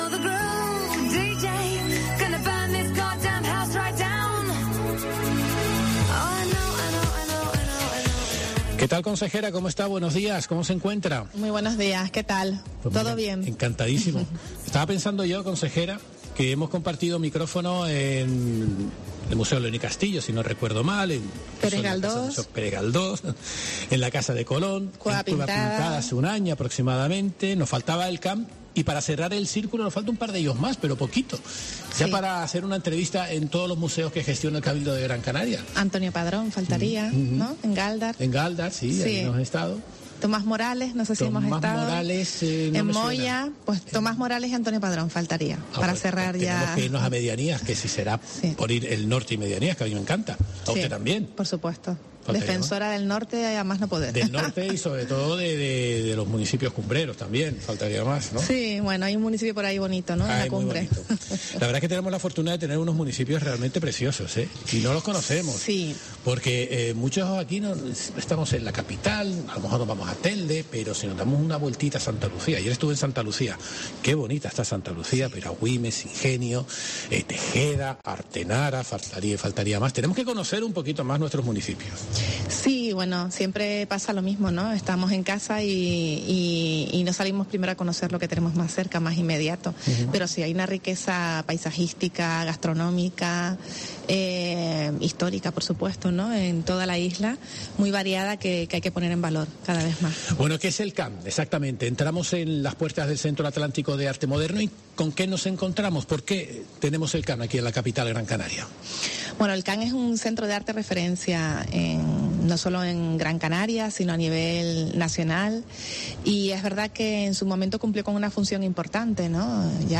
Guacimara Medina, consejera de Cultura del Cabildo de Gran Canaria